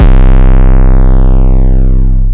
808s
808 - FUNKY.wav